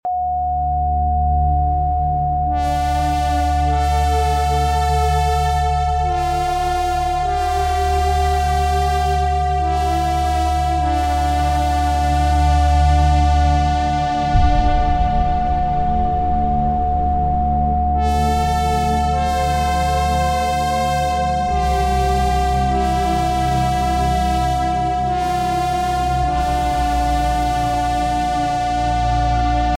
😱🧐 La frecuencia Rife de 727 Hz se ha utilizado en terapias energéticas para tratar infecciones bacterianas como las causadas por Staphylococcus, que pueden provocar foliculitis (granos dolorosos e inflamados en los folículos pilosos). Esta frecuencia actúa como un apoyo vibracional para restaurar el equilibrio energético de la piel, favoreciendo su limpieza y recuperación.